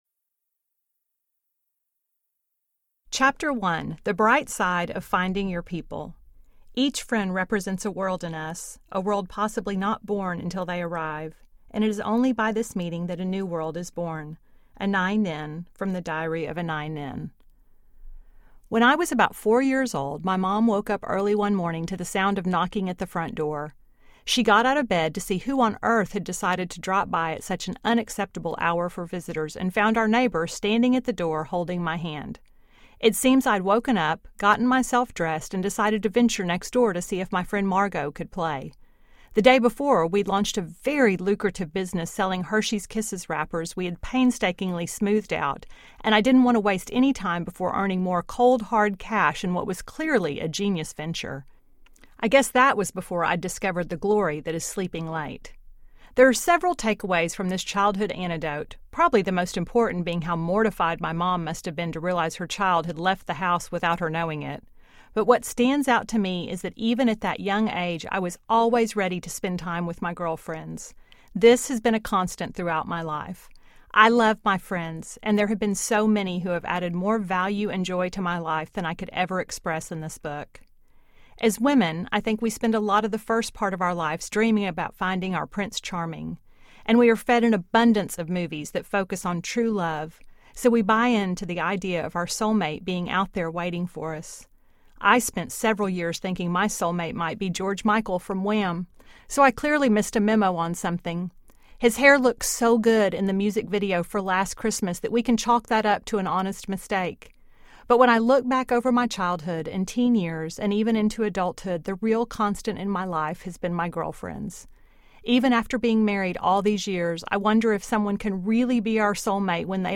On the Bright Side Audiobook